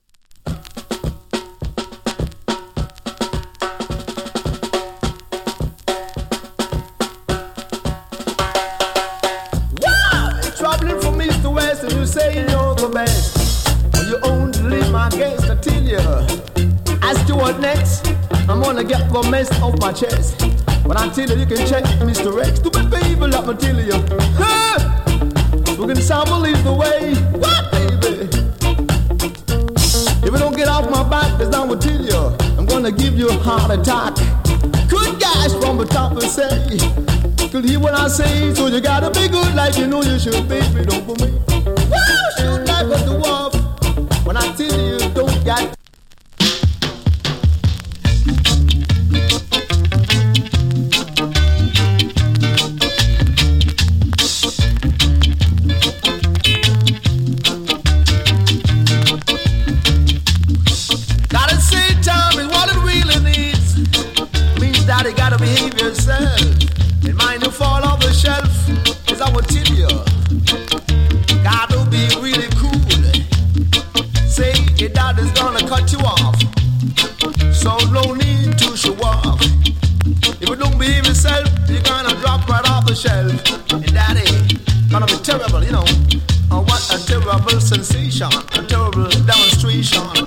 A：VG(OK) / B：VG(OK) ＊小キズ少し有り。スリキズ有り。チリ、ジリノイズ少々有り。
FUNKY REGGAE
NICE DEE-JAY